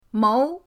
mou2.mp3